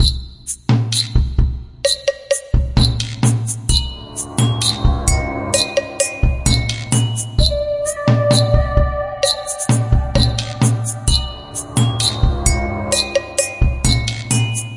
一个奇怪的130bpm循环，有一些牛铃和其他打击乐器。